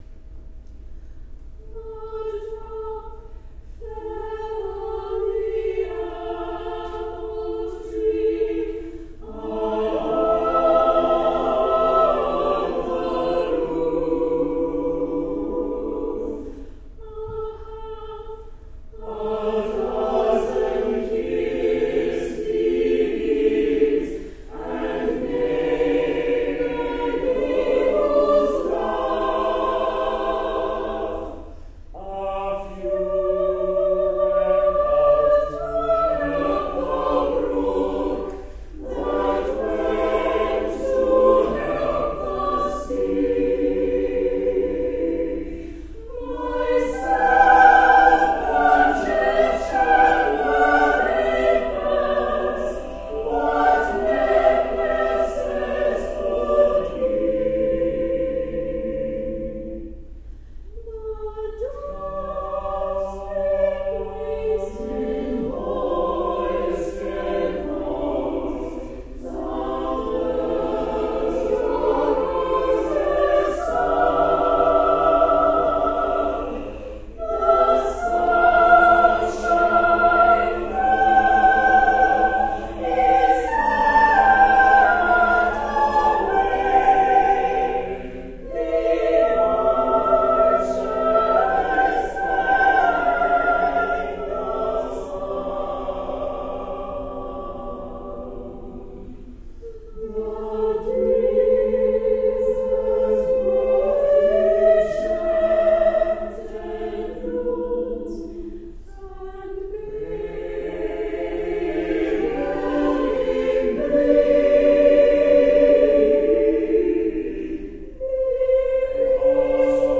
A choral setting for Emily Dickinson’s
Church of the Transfiguration Choir